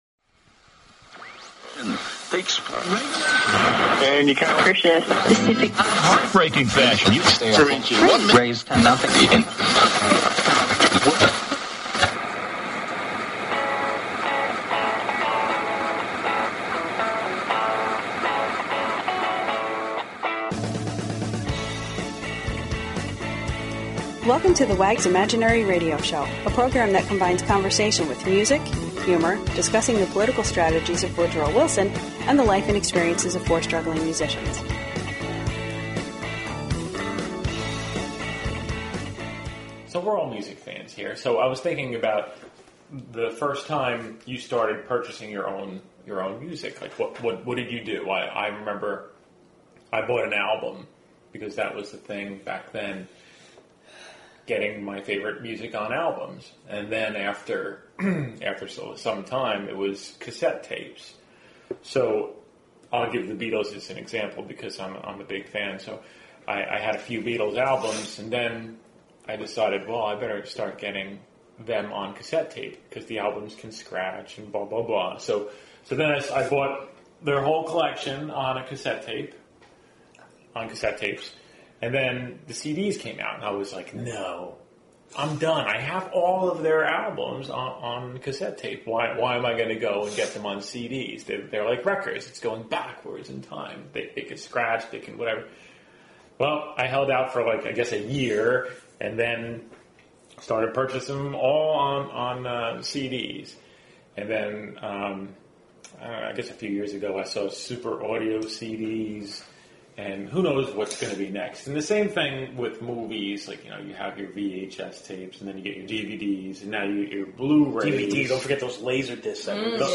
Talk Show Episode, Audio Podcast, The_Wags_Imaginary_Radio_Show and Courtesy of BBS Radio on , show guests , about , categorized as
It is a podcast that combines conversation with music, humor & the life experiences of four struggling musicians from New Jersey.